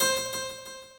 harp3.ogg